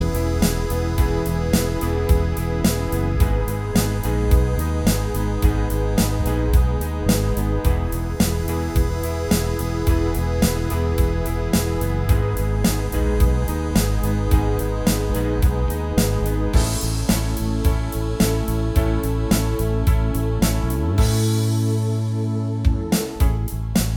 No Guitars Pop